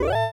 bip_05.wav